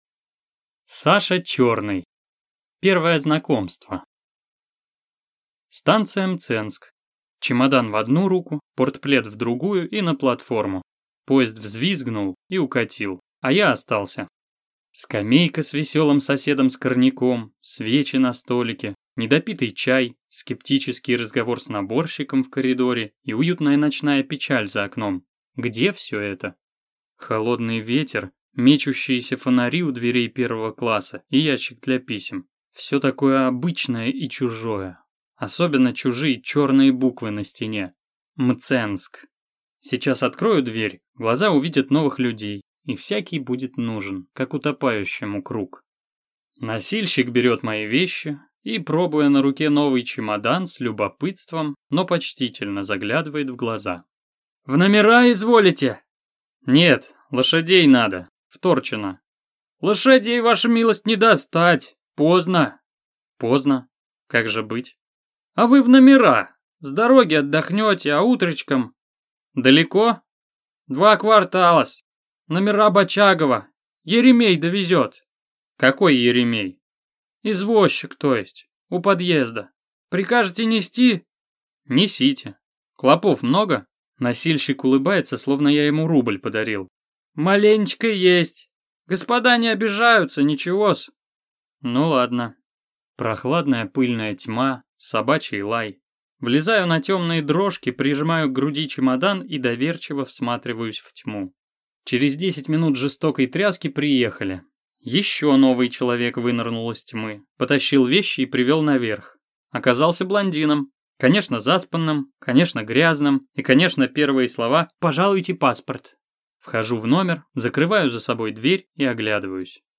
Аудиокнига Первое знакомство | Библиотека аудиокниг